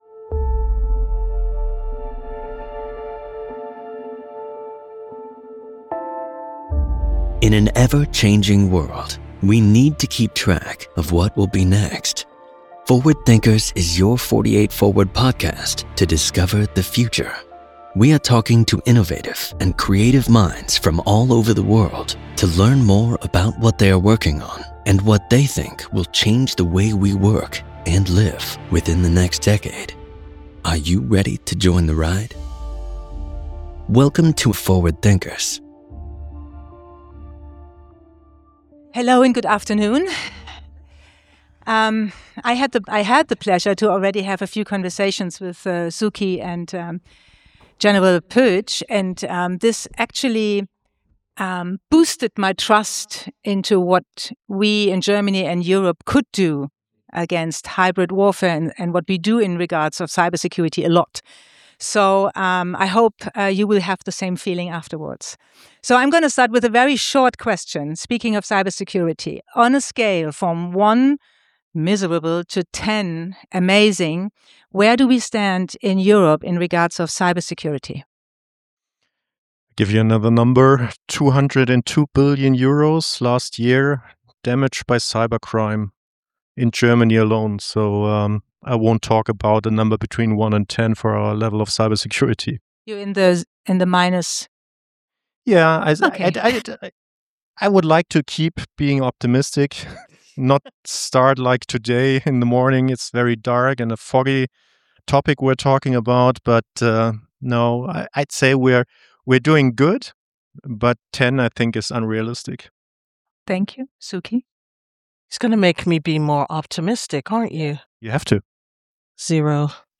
Ein Gespräch beim 48forward Festival, das zeigt, wie verwundbar Europa im digitalen Raum geworden ist – und warum Wachsamkeit längst zur demokratischen Grundhaltung zählt.